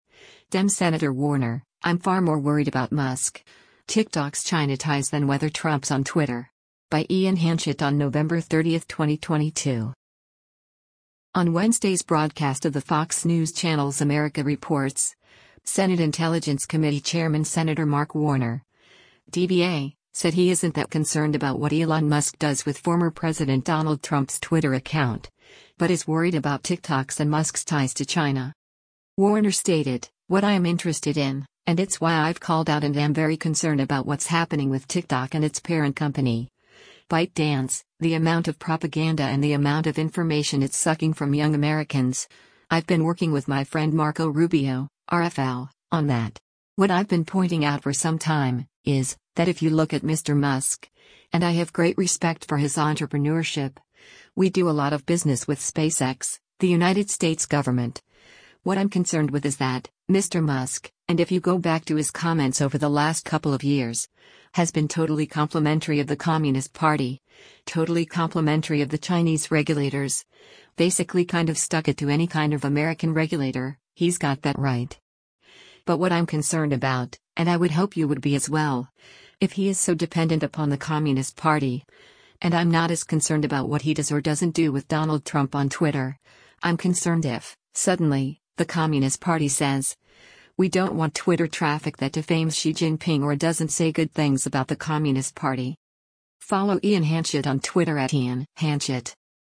On Wednesday’s broadcast of the Fox News Channel’s “America Reports,” Senate Intelligence Committee Chairman Sen. Mark Warner (D-VA) said he isn’t that concerned about what Elon Musk does with former President Donald Trump’s Twitter account, but is worried about TikTok’s and Musk’s ties to China.